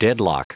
Prononciation du mot deadlock en anglais (fichier audio)
Prononciation du mot : deadlock